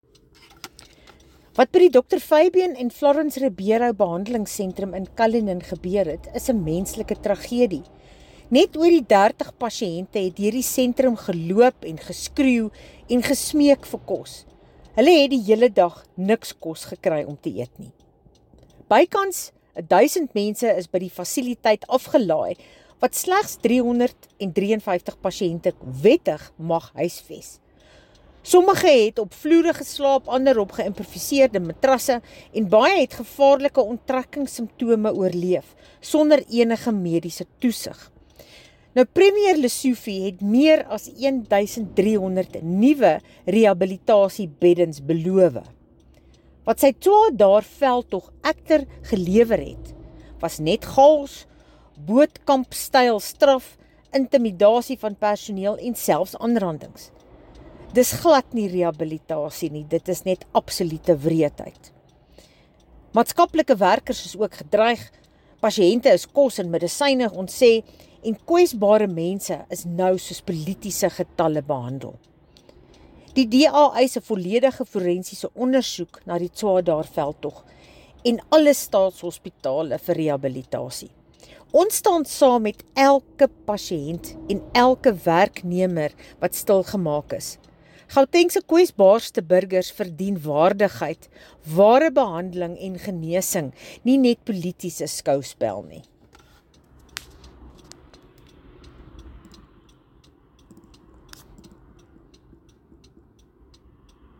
Afrikaans soundbites by Bronwynn Englebrecht MPL.